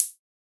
edm-hihat-07.wav